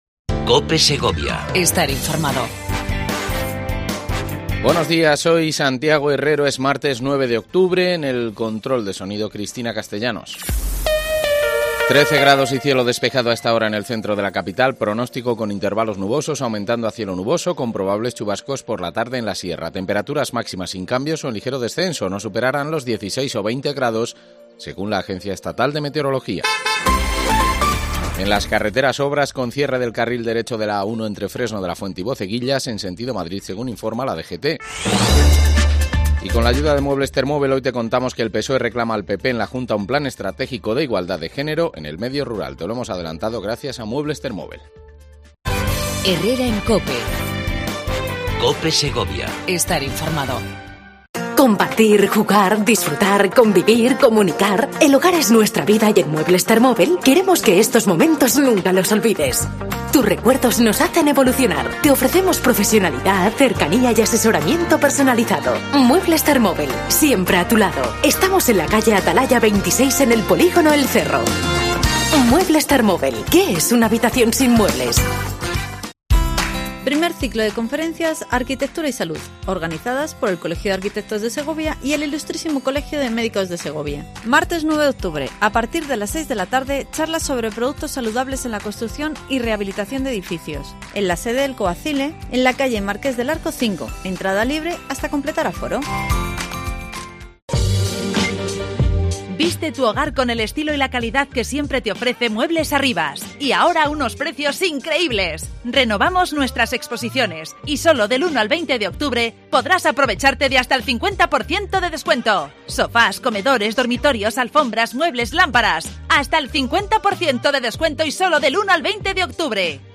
AUDIO: Entrevista a Sara Dueñas, diputada delegada del área de cultura y juventud